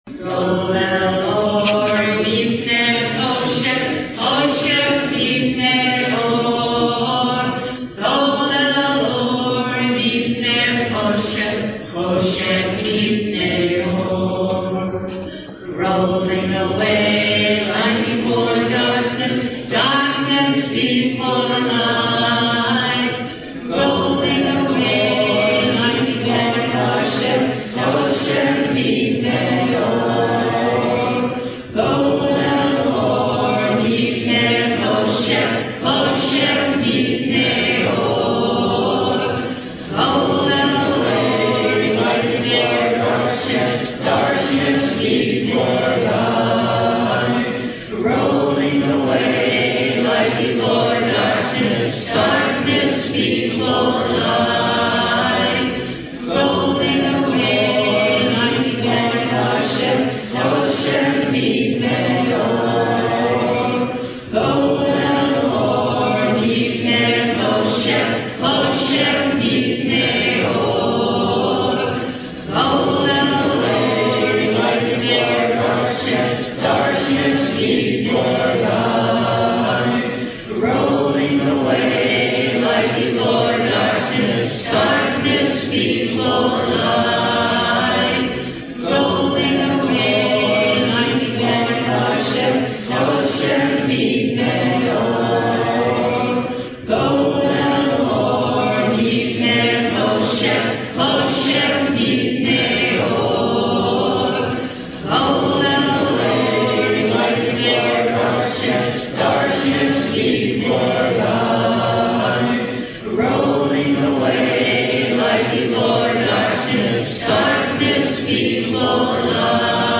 (Recorded at Temple Isaiah March 1 2013 - WRJ Worldwide Shabbat Service)
As the repetitions of the chant wash over you, let the spiraling sounds pull you gently in; let the knowledge that there will be light coming soon draw you into and through the darkness.